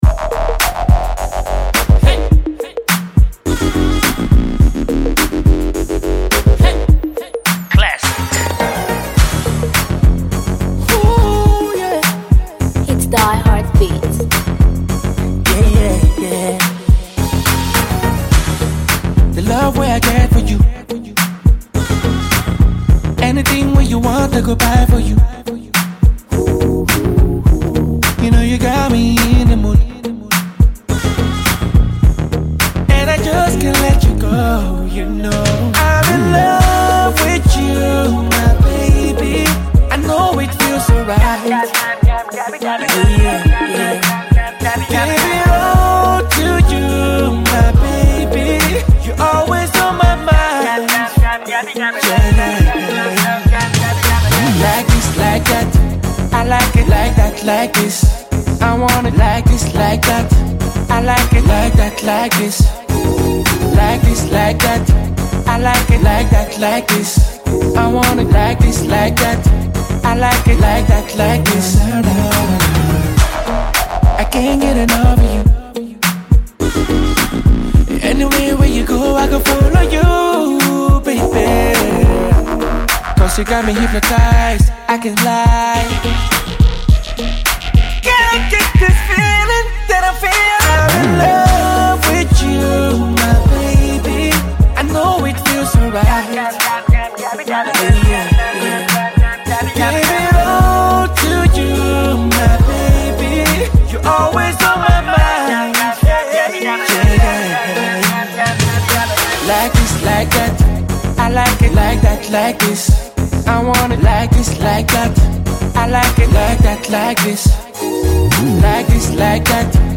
club banger